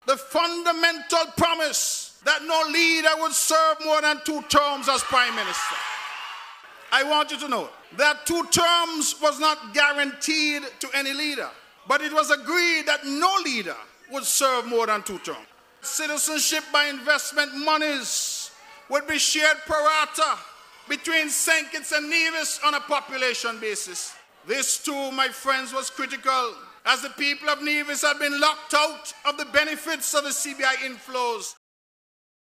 Featured Speaker at PAM’s Convention says keeping the Promise remains “critical”
The Convention was held on Sunday, 13th March, 2022 in Cayon, St. Kitts, under the theme: “Keeping the Promise”.